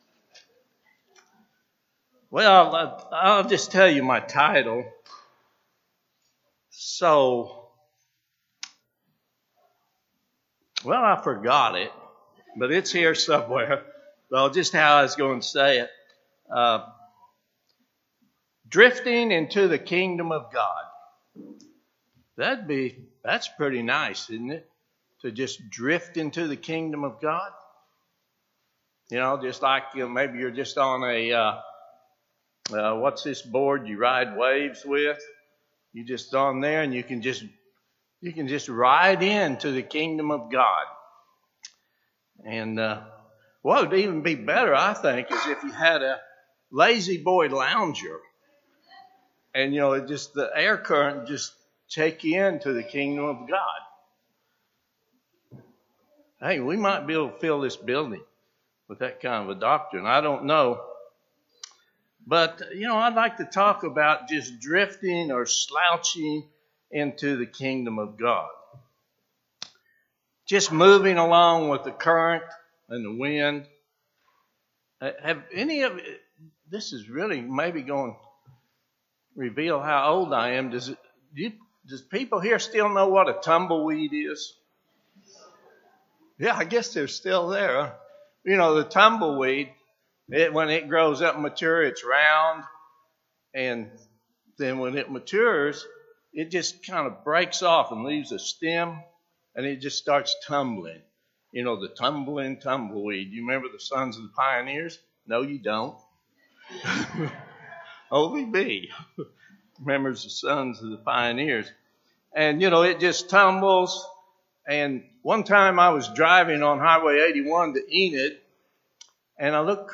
Given in Oklahoma City, OK